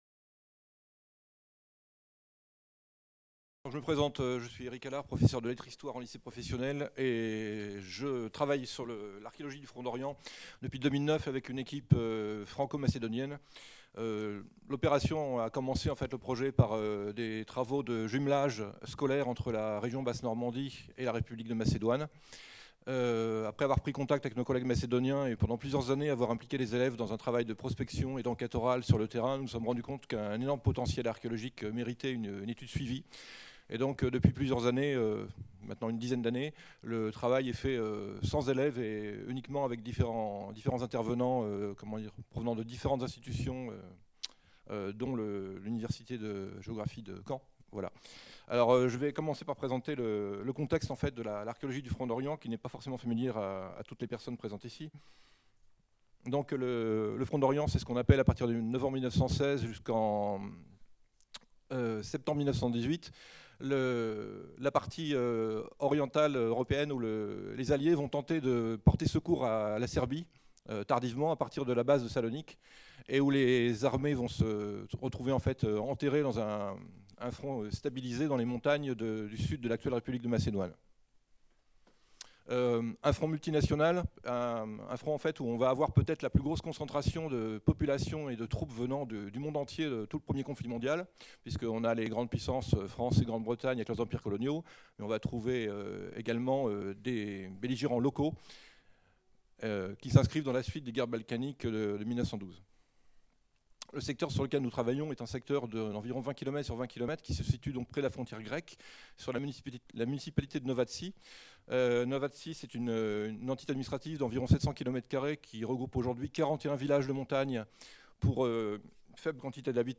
Cette communication a été filmée lors du colloque international intitulé De Verdun à Caen - L'archéologie des conflits contemporains : méthodes, apports, enjeux qui s'est déroulé au Mémorial de Caen les 27 et 28 mars 2019, organisé par la DRAC Normandie, la DRAC Grand-Est, l'Inrap et l'Université de Caen (MRSH-HisTeMé) avec le partenariat de la Région Normandie, du Département du Calvados, de la Ville de Caen et du Groupe de recherches archéologiques du Cotentin. Depuis plus d’une décennie, l’archéologie contemporaine s’est approprié ce nouvel objet d’étude que sont les conflits de l’ère contemporaine.